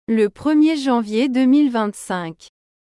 le 1er janvier 2025ル プルミェ ジョンヴィエ ドゥミルヴァンサンク